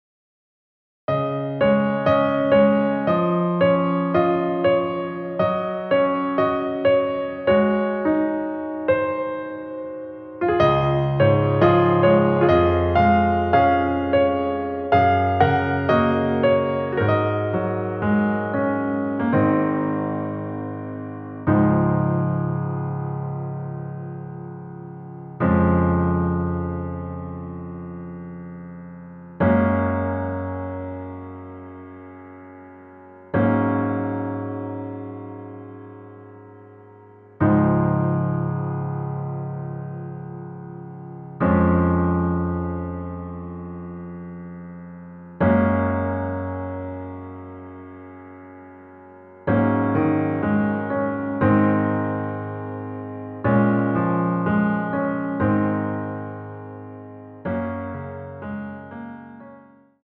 Db
앨범 | O.S.T